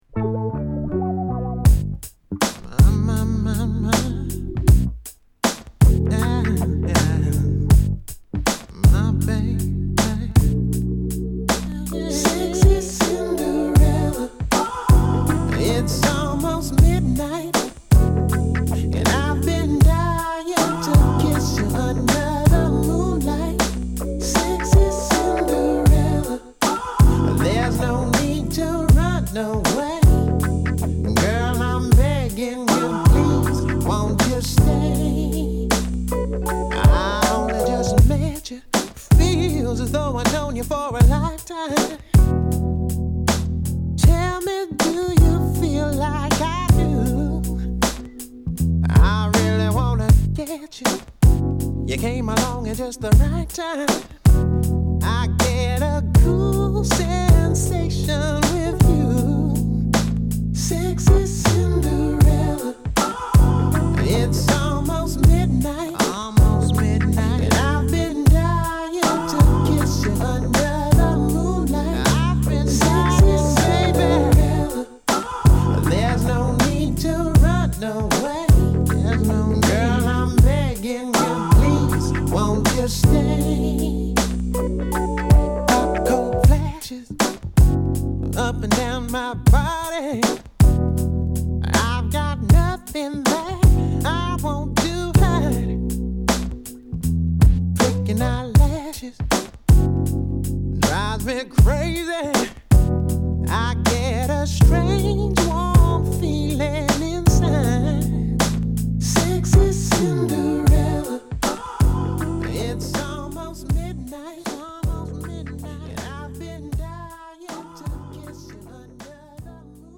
期待を裏切らないニュークラシックソウルを披露！